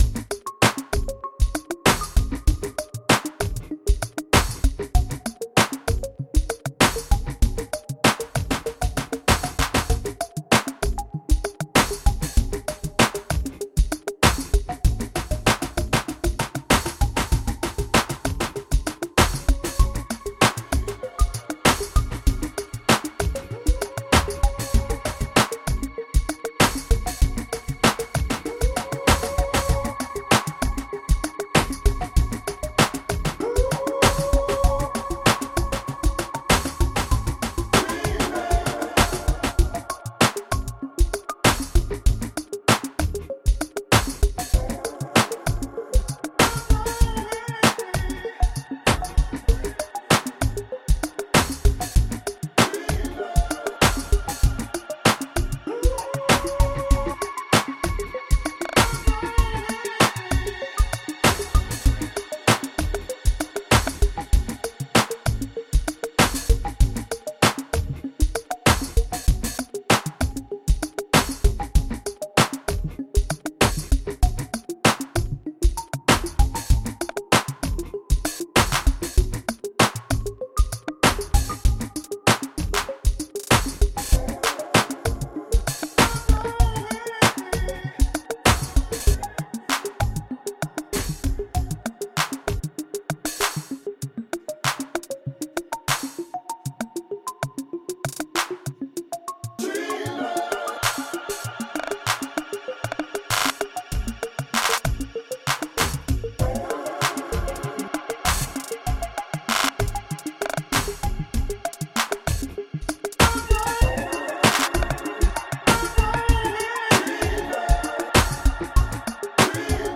Never get tired of Teddy Riley and new jack swing!